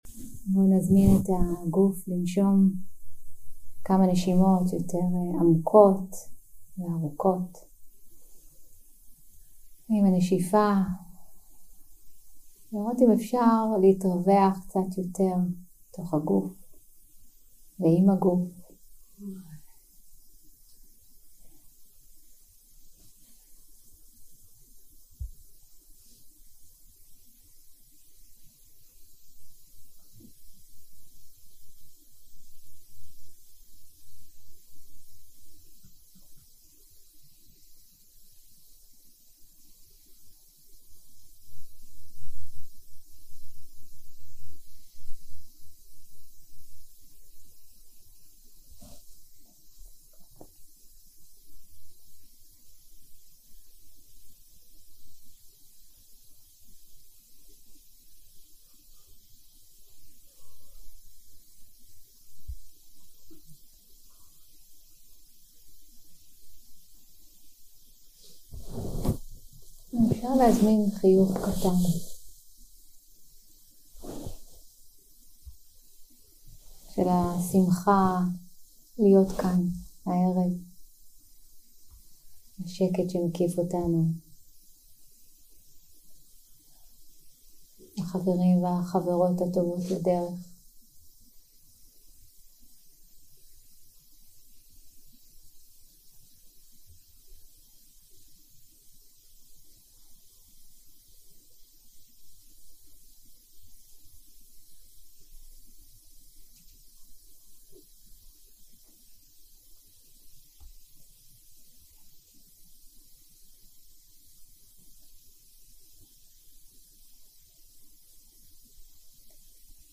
יום 1 – הקלטה 1 – ערב – הנחיות למדיטציה – פתיחה להתהוות מותנית 0:00 0:00 סוג ההקלטה: Dharma type: Guided meditation שפת ההקלטה: Dharma talk language: Hebrew